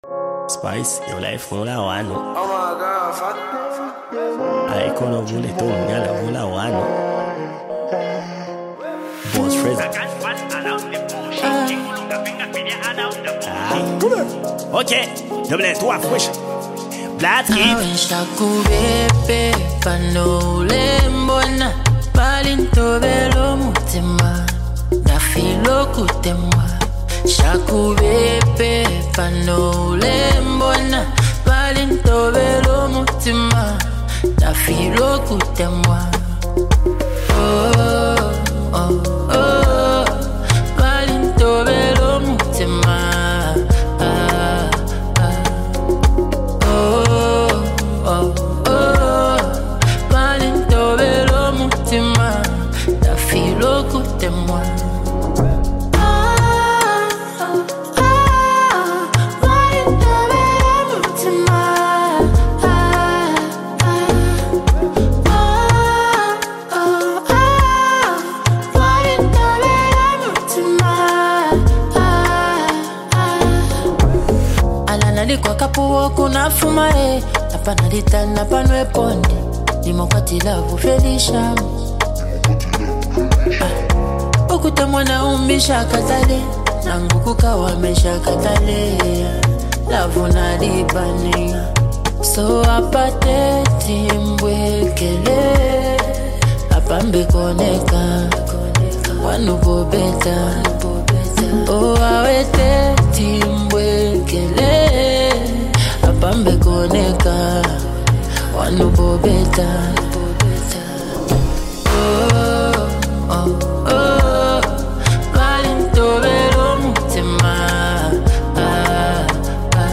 deliver an emotional performance
smooth vocals
creating a perfect harmony.